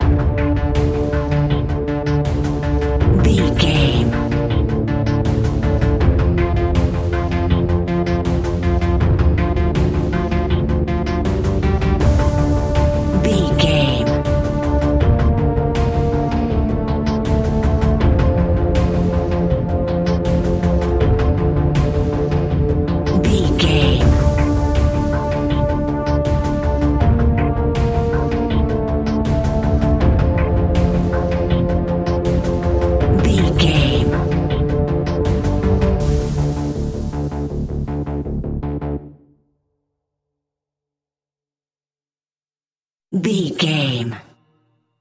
A great piece of royalty free music
Aeolian/Minor
ominous
dark
eerie
synthesiser
strings
drums
percussion
instrumentals
horror music